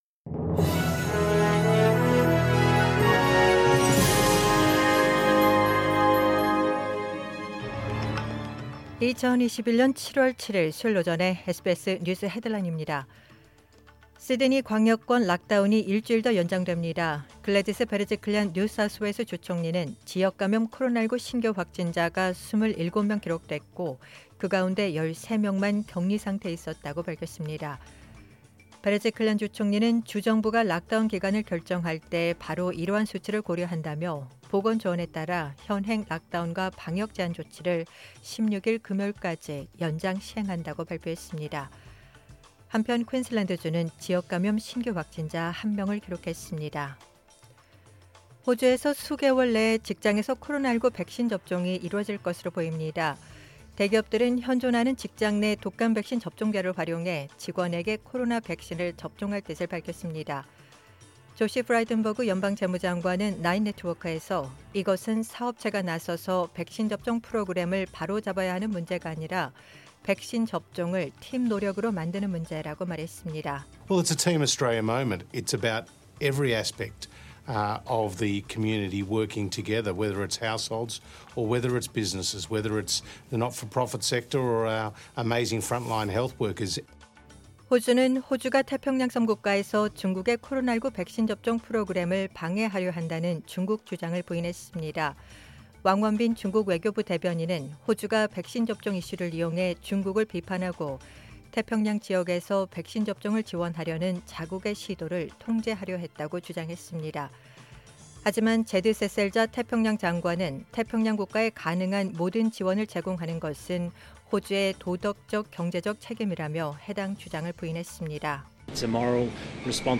2021년 7월 7일 수요일 오전의 SBS 뉴스 헤드라인입니다.